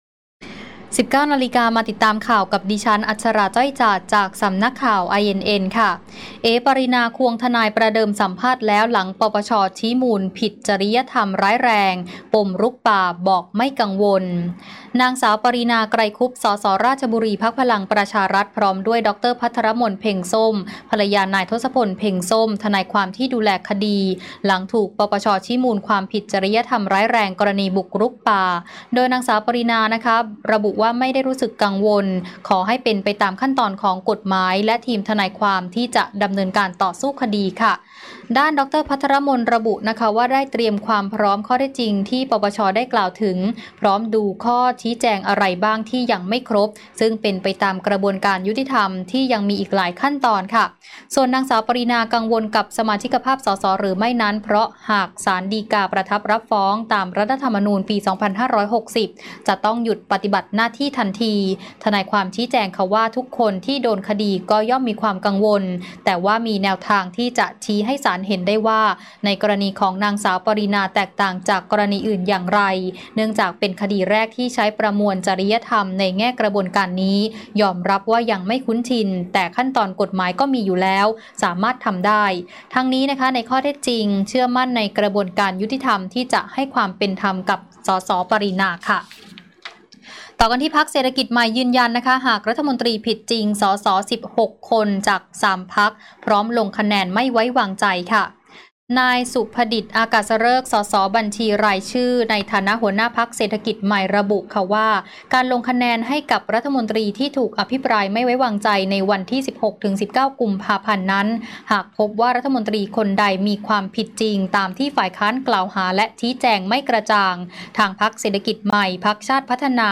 "เอ๋ ปารีณา" ควงทนาย สัมภาษณ์ หลัง ป.ป.ช. ชี้มูลผิดจริยธรรมร้ายแรงปมรุกป่า